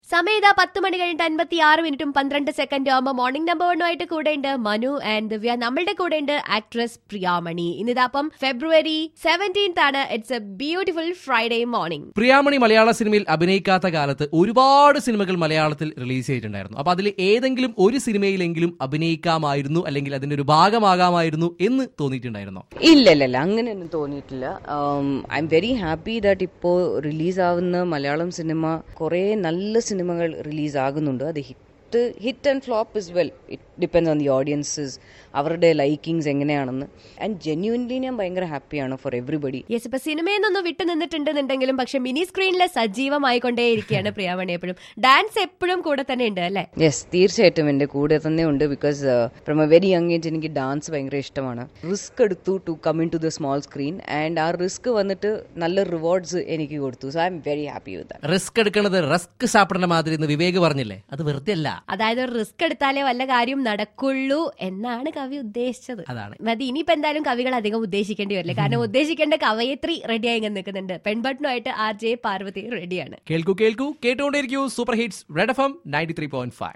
PRIYAMANI INTERVIEW